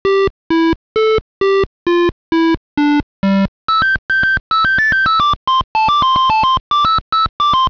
VGM：
V1Sモード：mkII音源